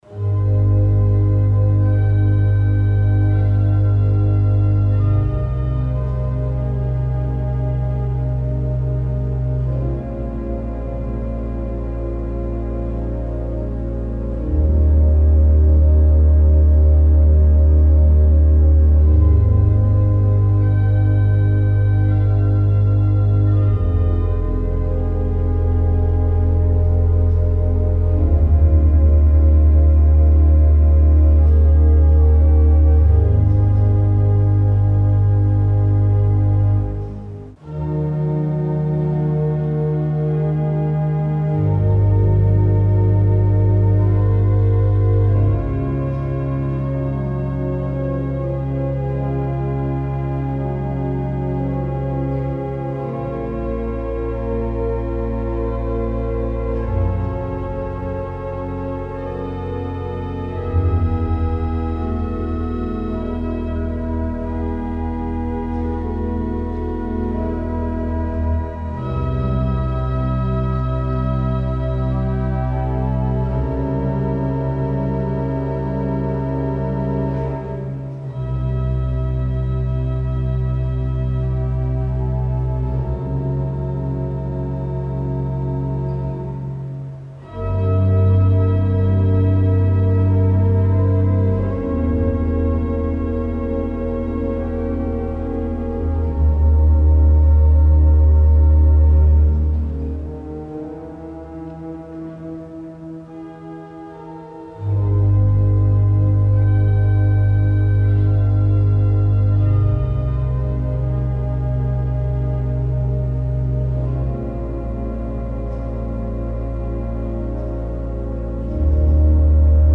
Grand orgue de la basilique
Orgue Louis Debierre 1895
L’orgue a conservé son esthétique romantique de 1895 mais les ajouts de jeux effectués lors du relevage de 1965 permettent de jouer un large répertoire, de la période classique à nos jours.
Orgue-de-Avesnieres_MIX.mp3